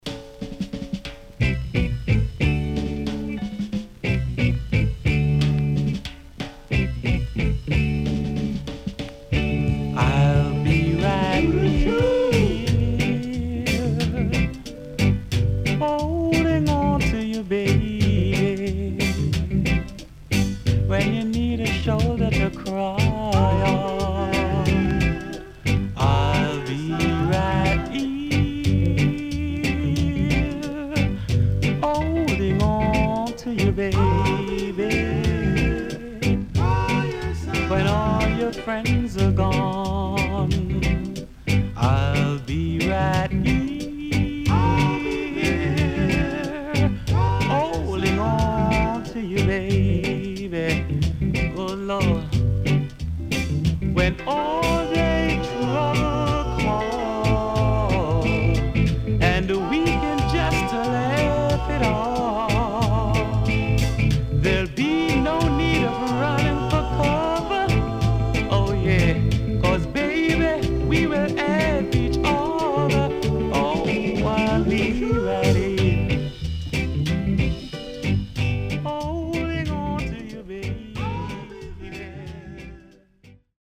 HOME > REGGAE / ROOTS  >  FUNKY REGGAE
Nice Funky Reggae & Sweet Vocal
SIDE A:プレスノイズと思われるチリノイズが所々入ります。